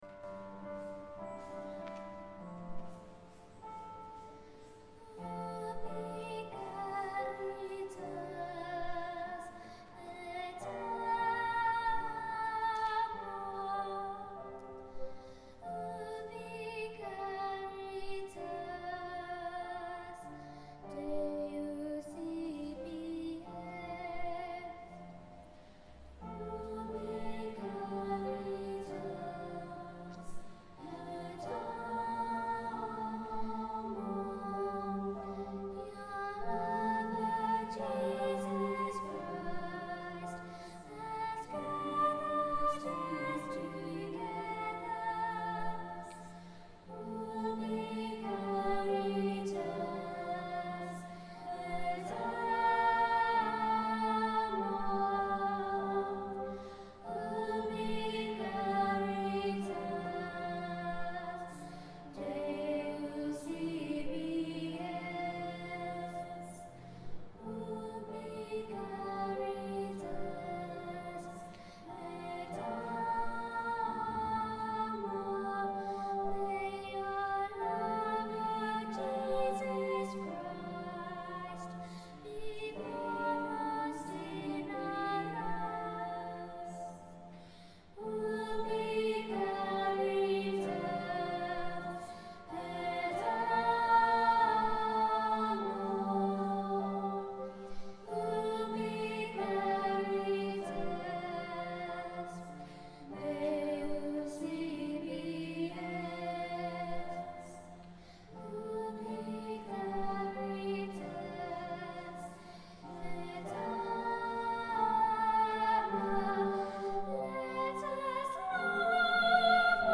This beautiful Taiz� hymn was rehearsed for First Holy Communion masses held in May 2008.
Recorded on the Zoom H4 digital stereo recorder at rehearsal, Tuesday 6th May 2008.